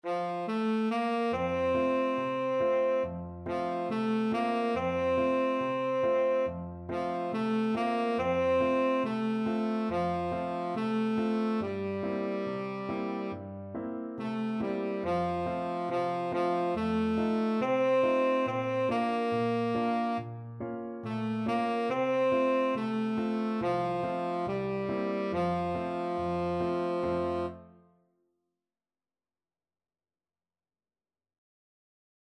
American gospel hymn.
4/4 (View more 4/4 Music)
Vivo =140